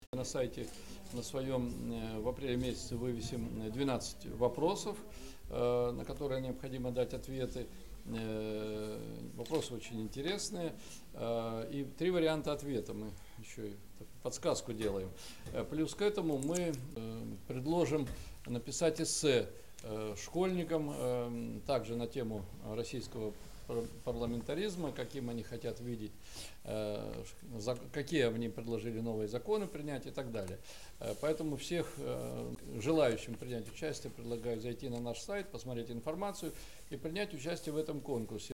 Об этом на встрече с журналистами в четверг, 4 апреля объявил председатель Законодательного собрания области Георгий Шевцов. Принять участие в конкурсе-викторине могут все ученики старших классов общеобразовательных школ Вологодской области.
Георгий Шевцов рассказал о конкурсе